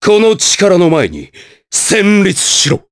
voices / heroes / jp
Nicx-Vox_Skill5_jp.wav